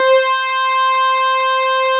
Added synth instrument
snes_synth_060.wav